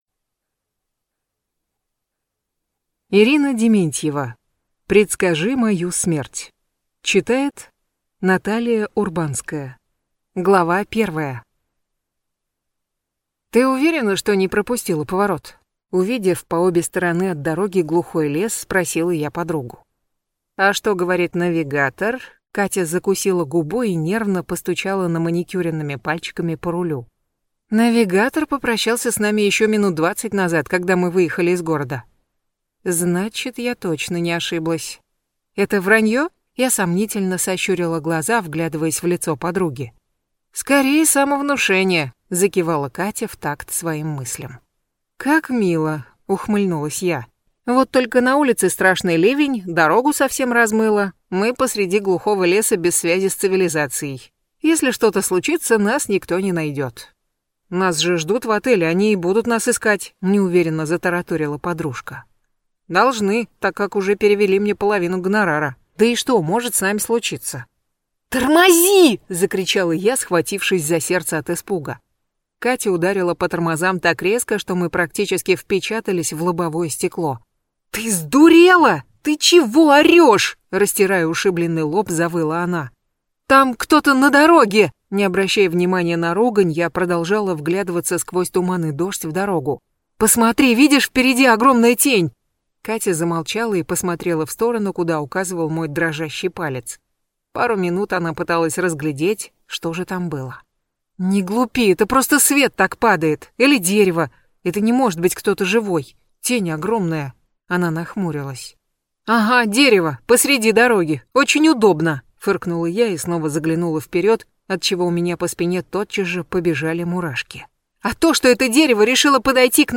Аудиокнига Предскажи мою смерть | Библиотека аудиокниг